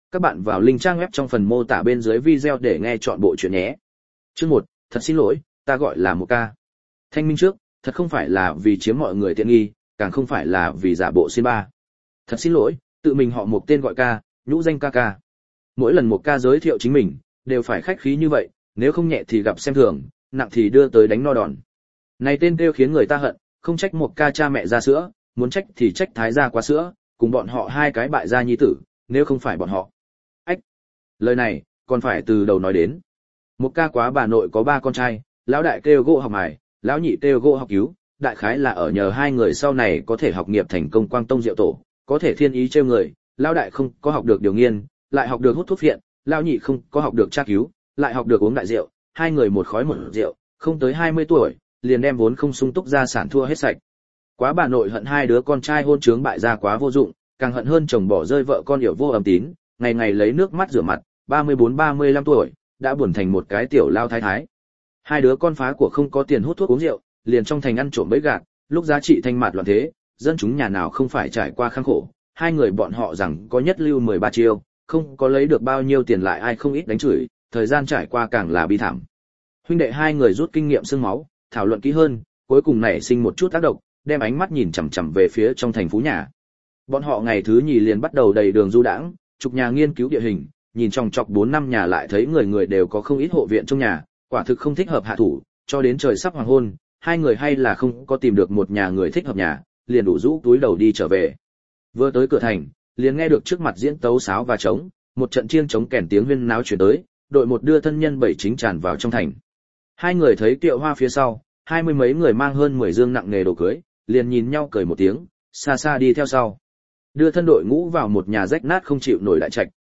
Đừng Cười Ca Bắt Quỷ Đây Audio - Nghe đọc Truyện Audio Online Hay Trên TH AUDIO TRUYỆN FULL